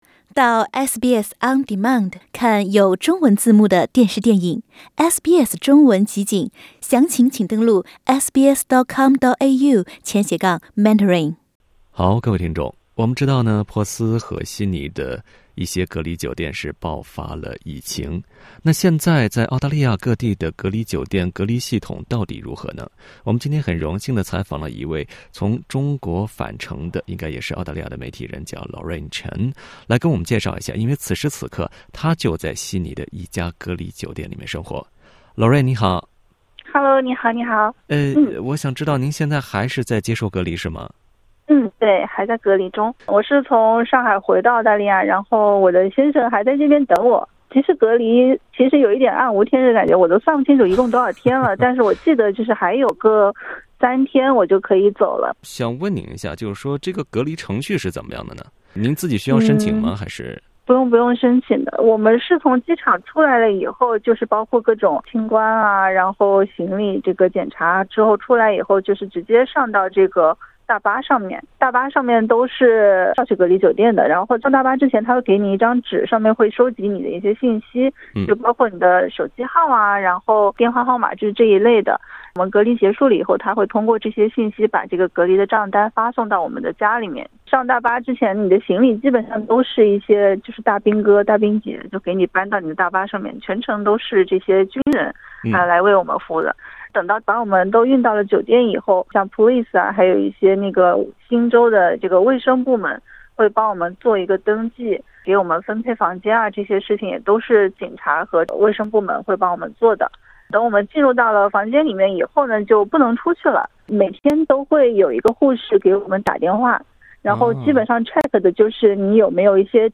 （點擊音頻，收聽寀訪）